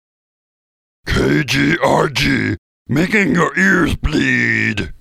Station Bumper-Making Your Ears Bleed!